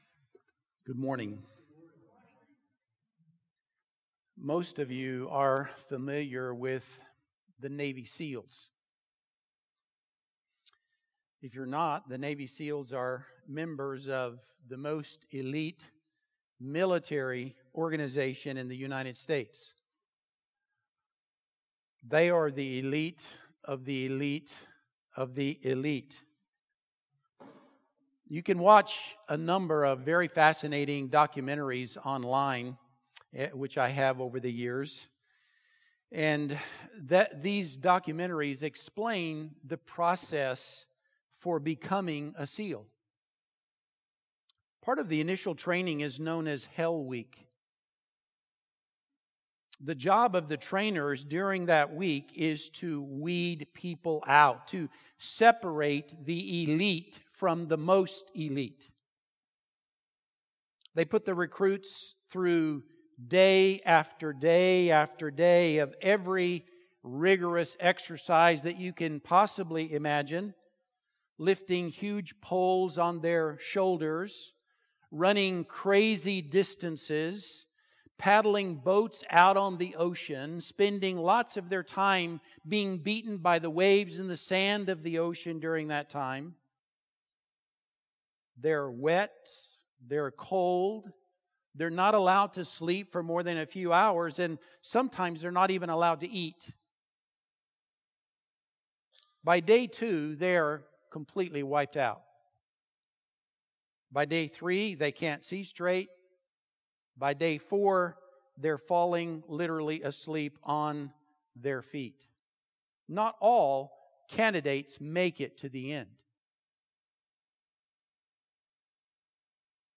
Good Friday service. 2024